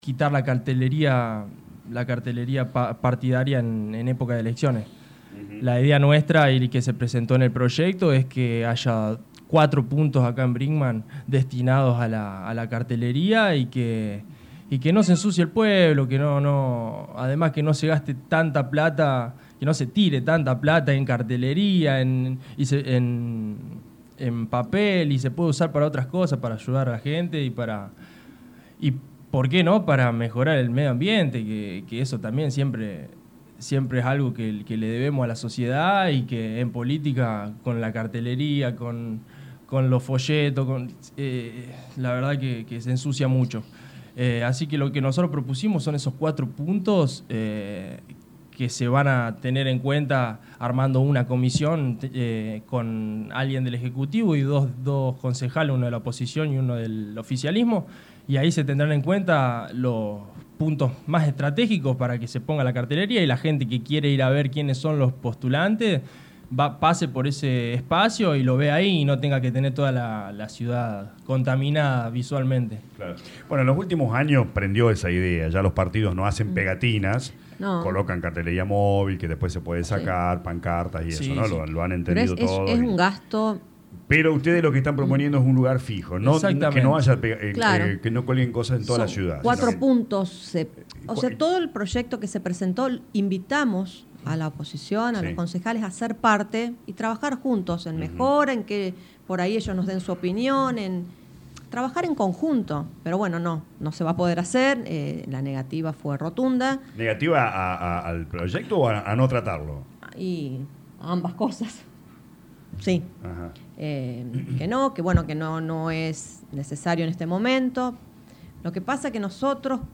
Los concejales Mariela Tavano e Hipólito Tomati de visita a los estudios de LA RADIO 102.9 FM volvieron a reclamar por la falta de respuesta del ejecutivo al pedido de informes.